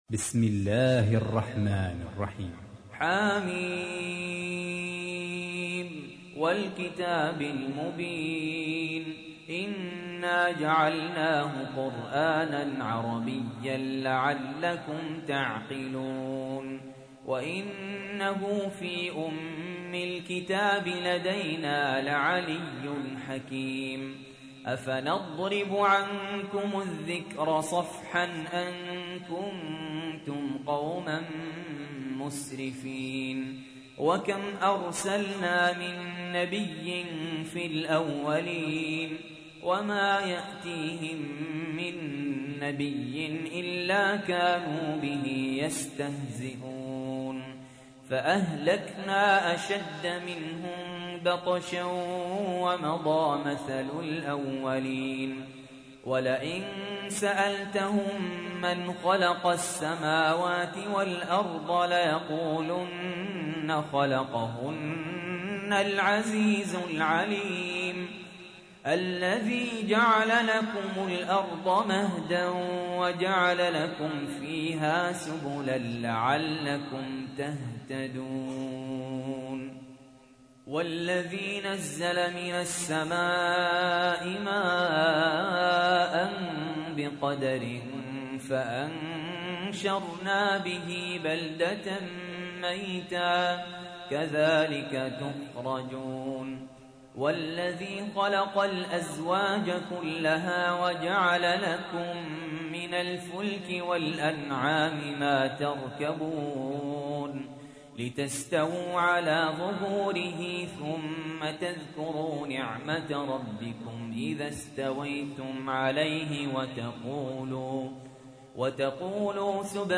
تحميل : 43. سورة الزخرف / القارئ سهل ياسين / القرآن الكريم / موقع يا حسين